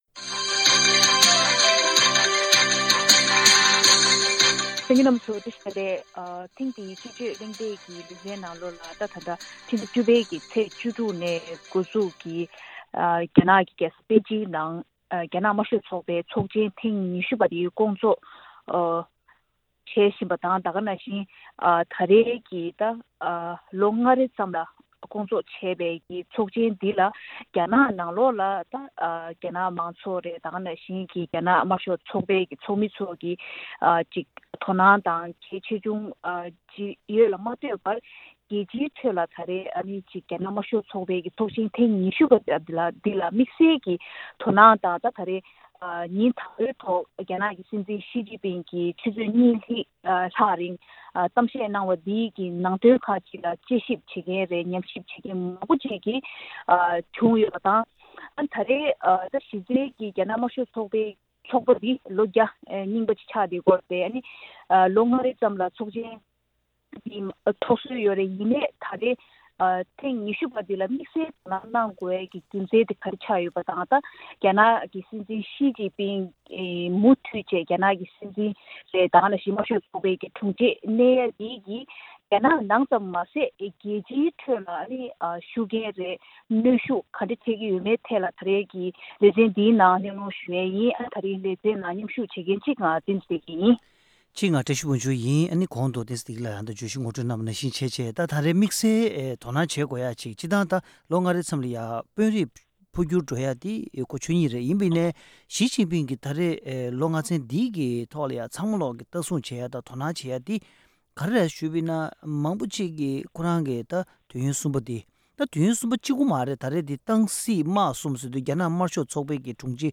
ཐེངས་འདིའི་བདུན་རེའི་དཔྱད་བརྗོད་གླེང་སྟེགས་ཀྱི་ལས་རིམ་ནང་།